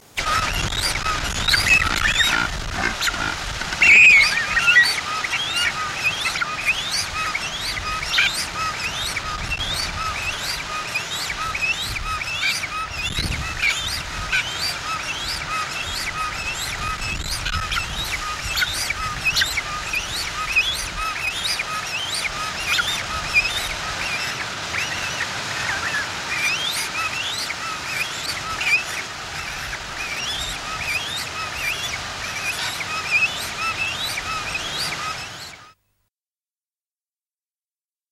Robin / Kakaruai or toutouwai - Project Janszoon
Like the tomtit, you may first become aware of the presence of robins from the loud, strident territory song that can be heard for much of the year. Males, and in particular bachelors, can be very vocal.
Bird calls courtesy of NZ birds online